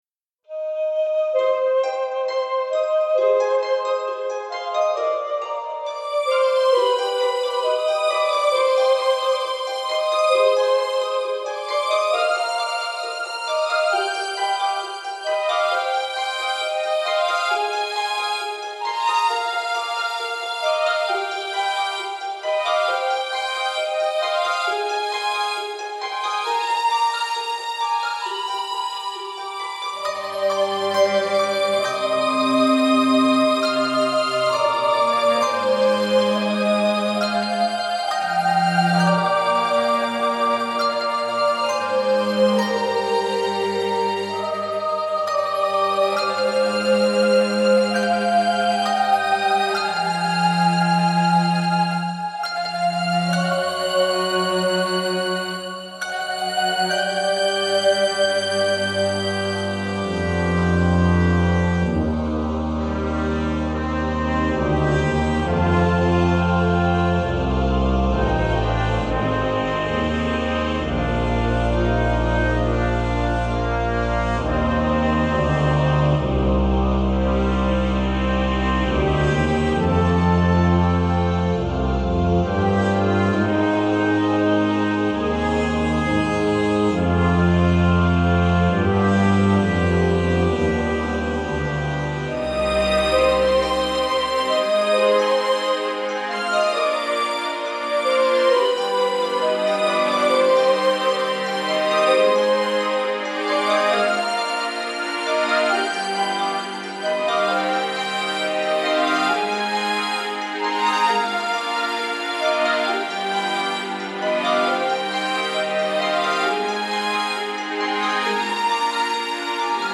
It has a great sense of discovery.